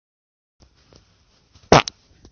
真实的屁 " 屁4
描述：真屁
标签： 现实 放屁 真正
声道立体声